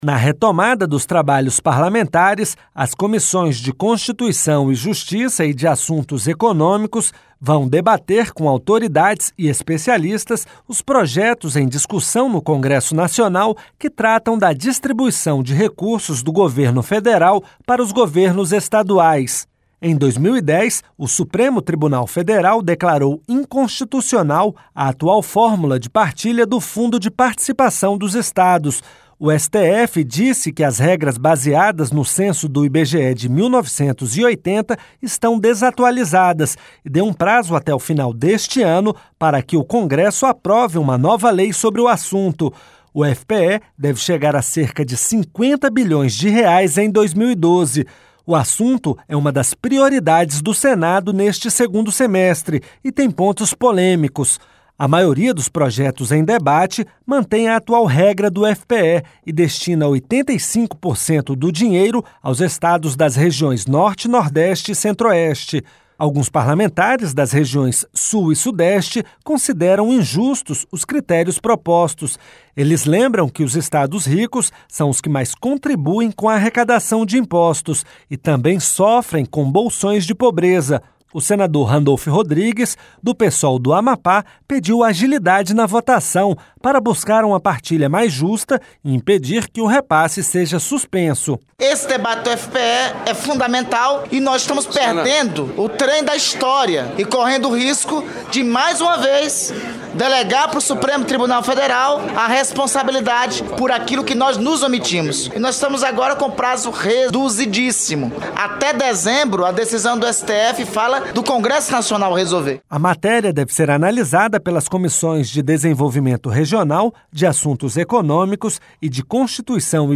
(REPÓRTER)